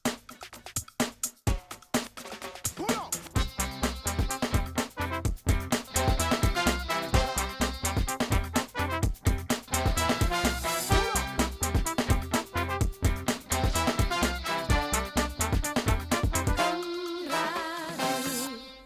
Indicatiu de l'estiu 2009